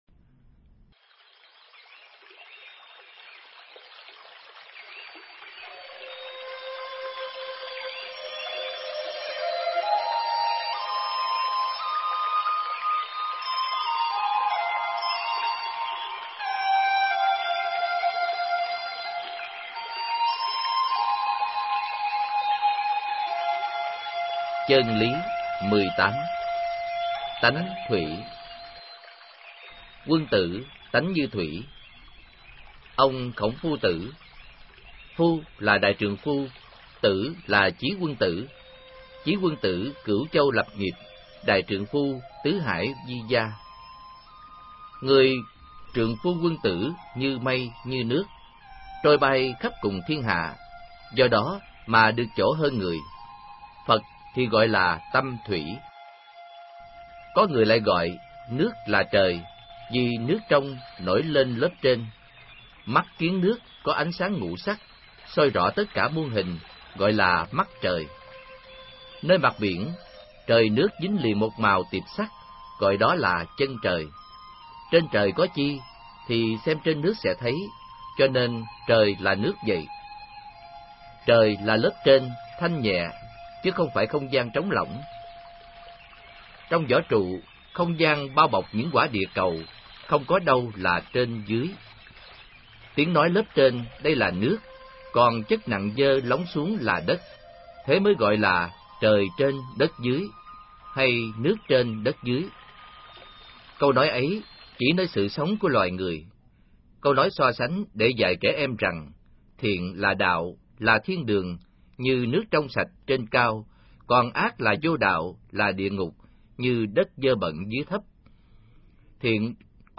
Nghe sách nói chương 18. Tánh Thuỷ